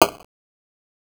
scavengers_chop2.aif